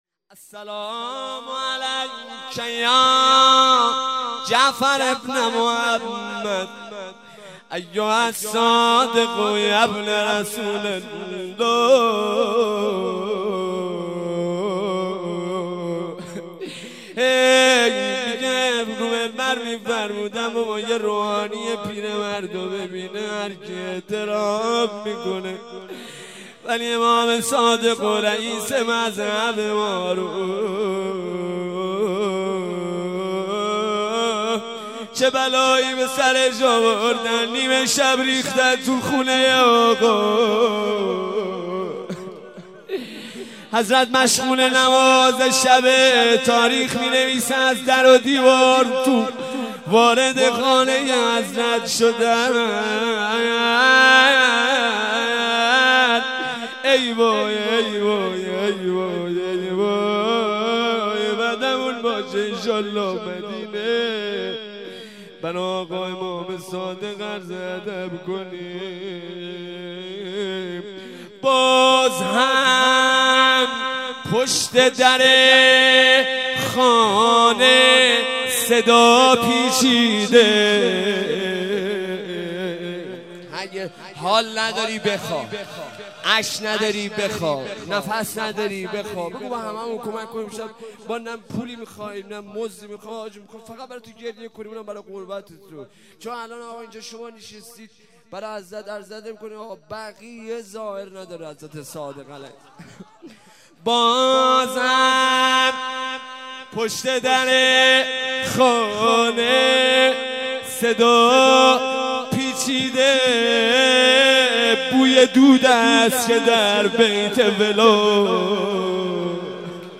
روضه1